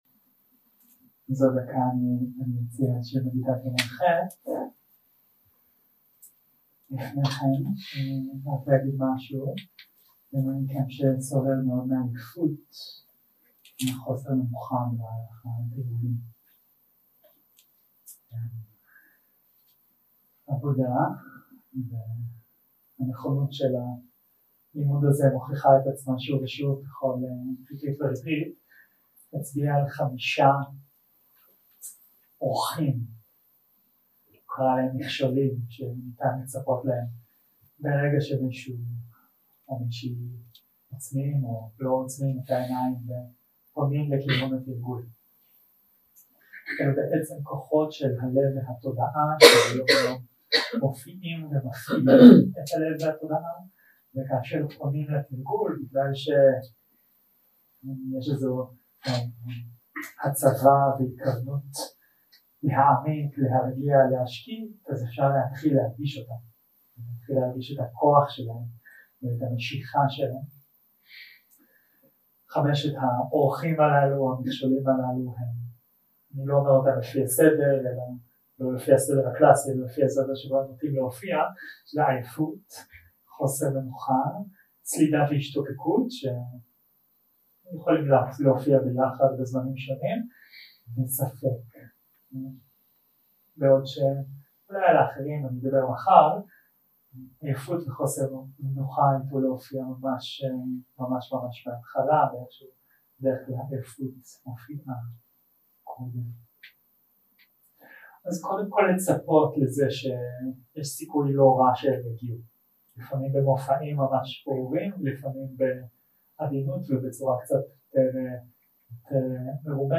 יום 2 – הקלטה 3 – צהריים – מדיטציה מונחית
Dharma type: Guided meditation